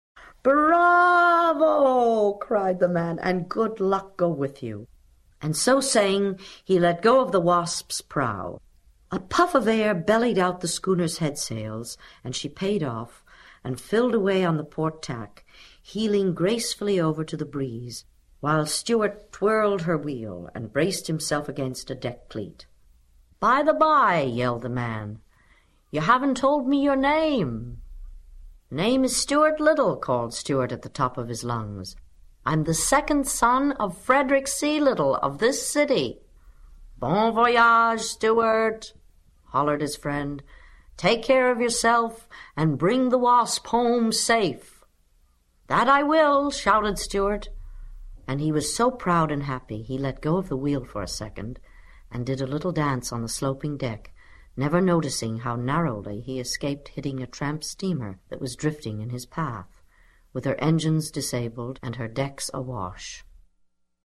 在线英语听力室精灵鼠小弟 第25期:骄傲和幸福的小鼠弟的听力文件下载, 《精灵鼠小弟》是双语有声读物下面的子栏目，是学习英语，提高英语成绩的极好素材。本书是美国作家怀特(1899—1985)所著的三部被誉为“二十世纪读者最多、最受爱戴的童话”之一。